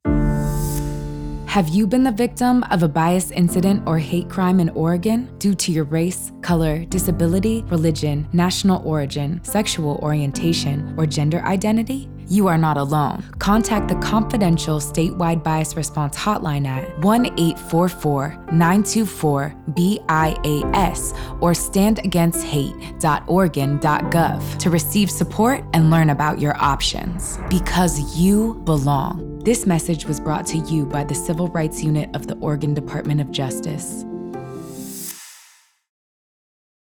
Radio
DOJ You Belong. Audio PSA (English – 33 sec)